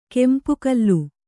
♪ kempu kallu